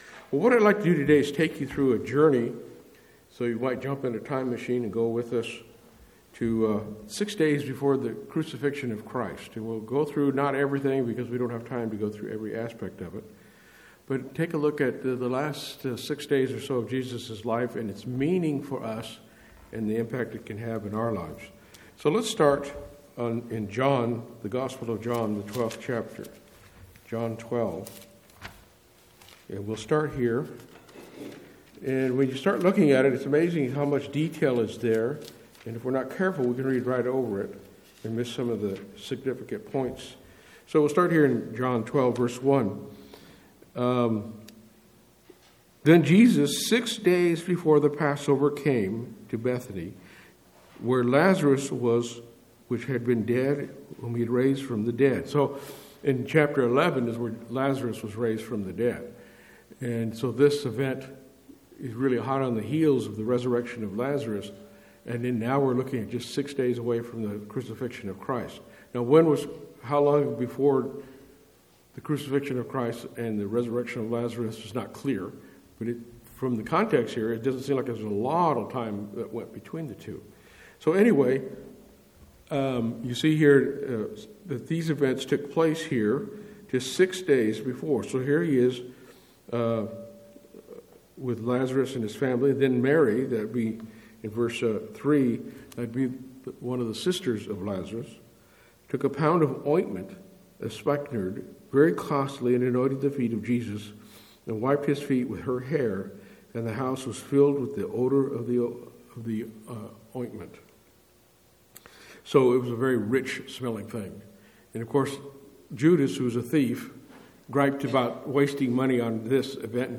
Given in Lawton, OK